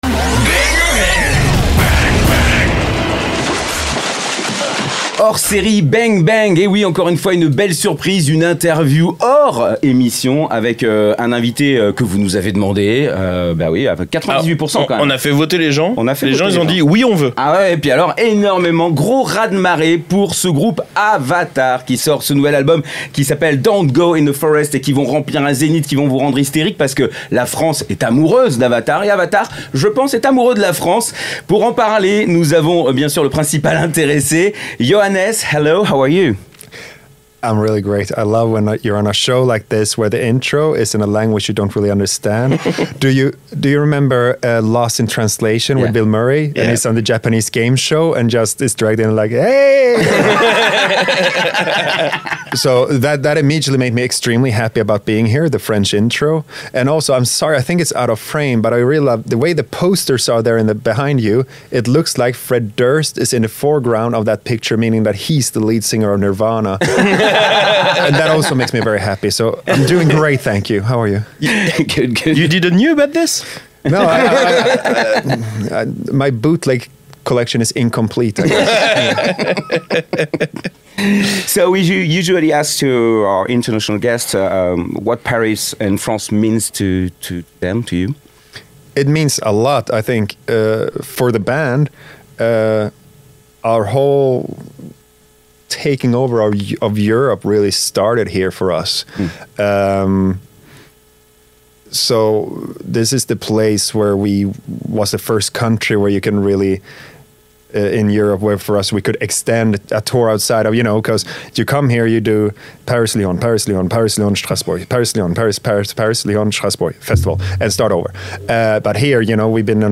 BANG! BANG! Interview : AVATAR - RSTLSS